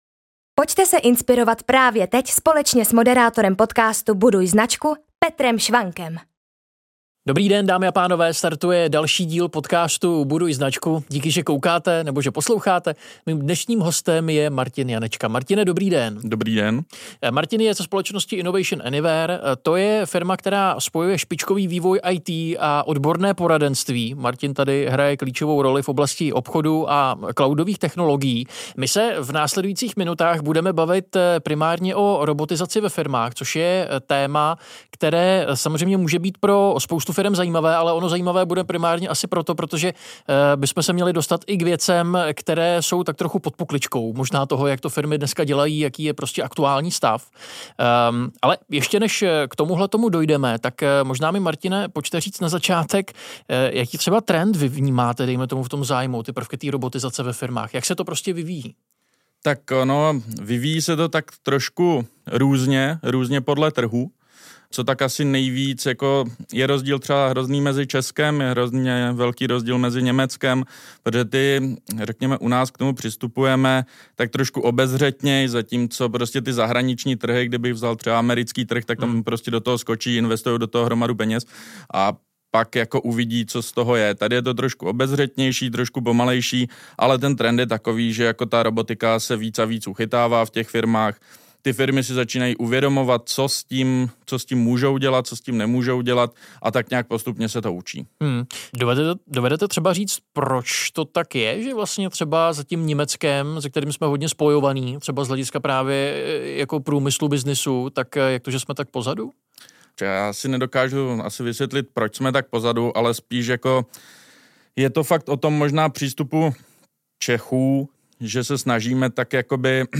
Tématem dnešního rozhovoru bude především softwarová robotizace a AI.